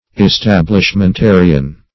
Search Result for " establishmentarian" : The Collaborative International Dictionary of English v.0.48: establishmentarian \es*tab`lish*men*ta"ri*an\, n. One who regards the Church primarily as an establishment formed by the State, and overlooks its intrinsic spiritual character.